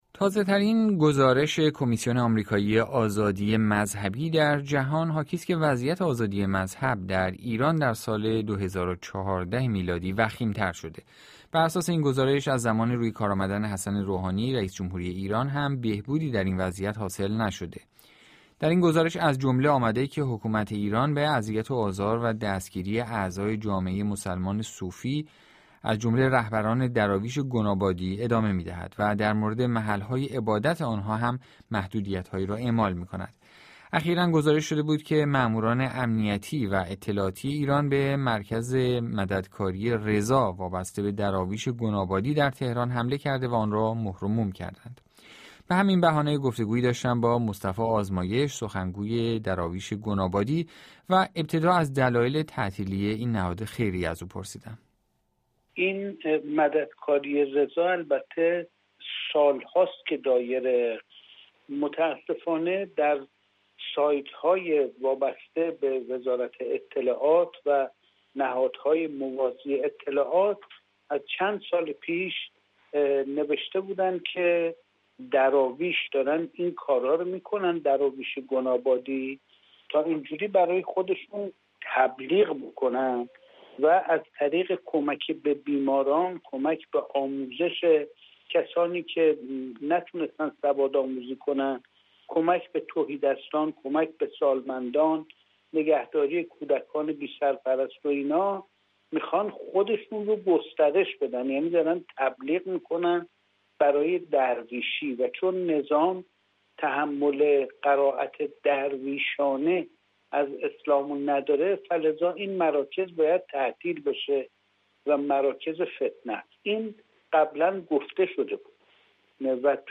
مصاحبه با بی‌بی‌سی